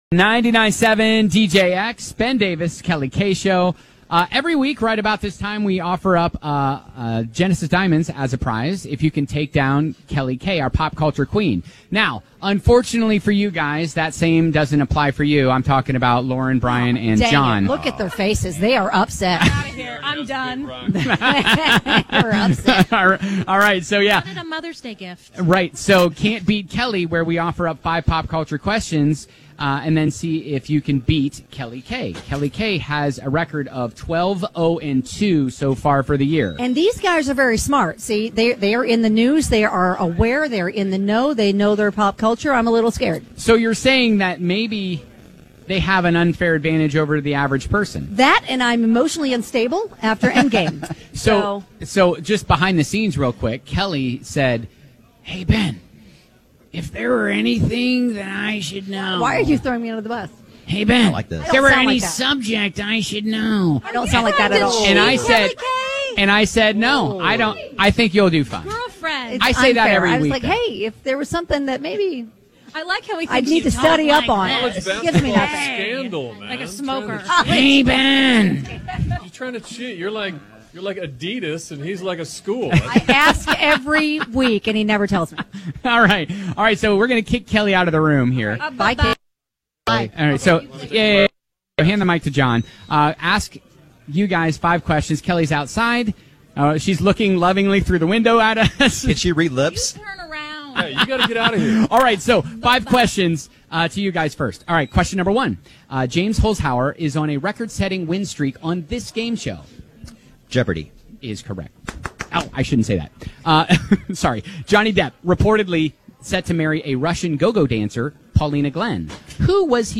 It's always fun to kick off Derby week backside at Churchill Downs with our media besties from WAVE3!